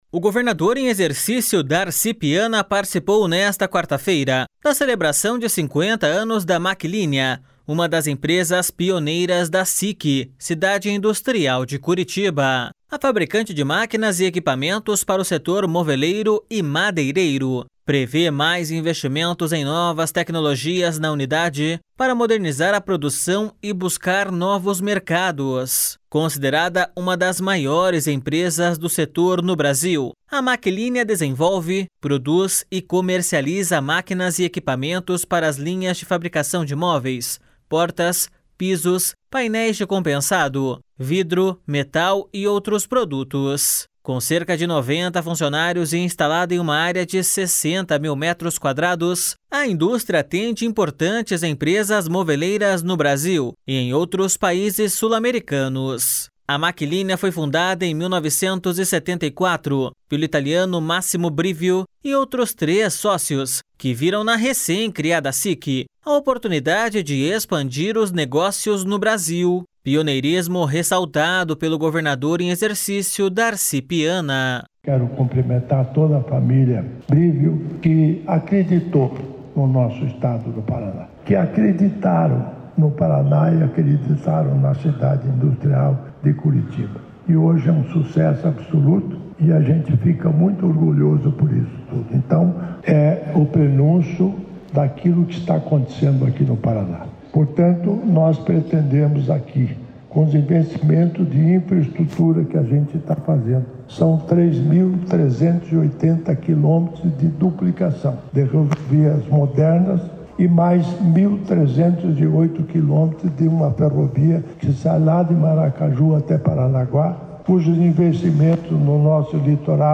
O governador em exercício Darci Piana participou nesta quarta-feira da celebração de 50 anos da Maclinea, uma das empresas pioneiras da CIC, Cidade Industrial de Curitiba. A fabricante de máquinas e equipamentos para o setor moveleiro e madeireiro prevê mais investimentos em novas tecnologias na unidade para modernizar a produção e buscar novos mercados.